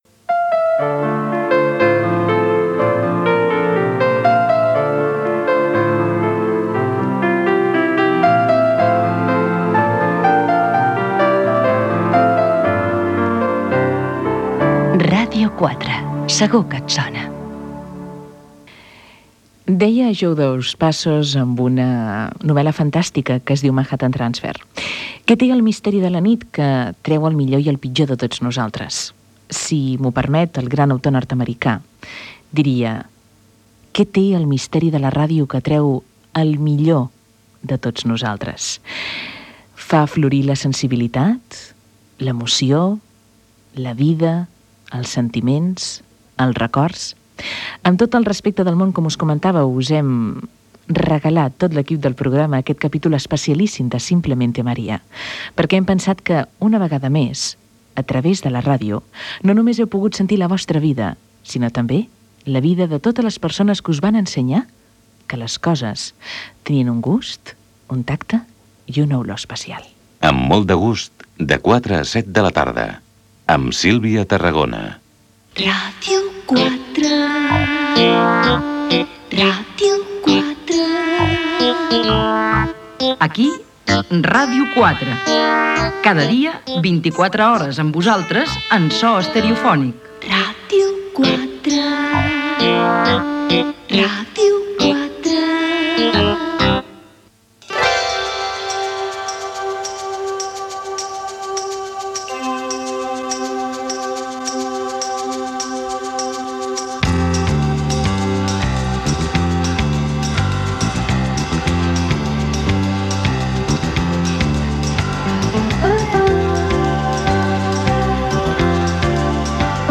Gènere radiofònic Entreteniment
Temps de Ràdio es va celebrar al CCCB de Barcelona.